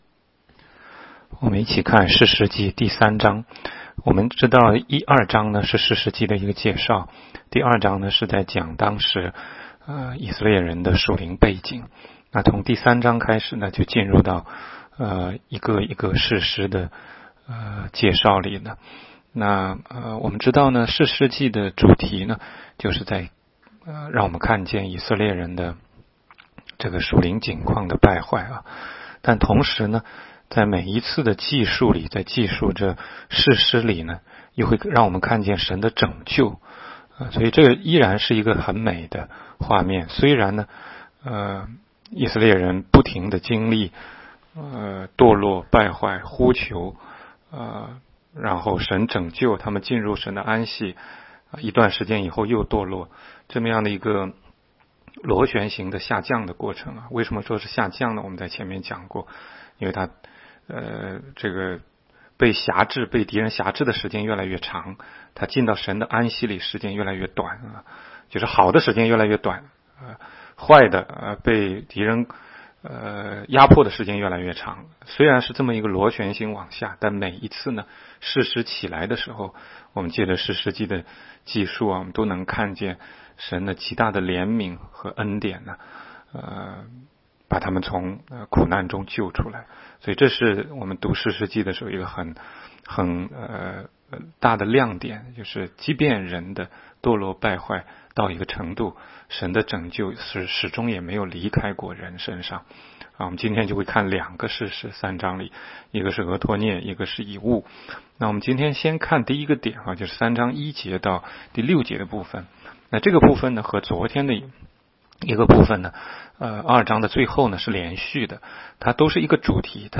16街讲道录音 - 每日读经-《士师记》3章